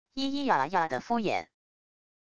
咿咿啊啊的敷衍wav音频